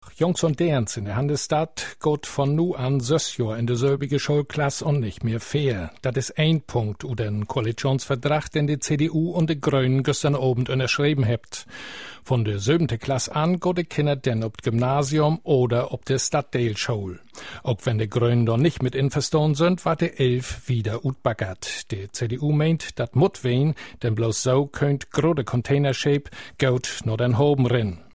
Here’s a recording from a news report in a mystery language.
The language is German, but there does seem to be an almost Dutch accent to it.
I think it’s Schwäbisch (Swabian).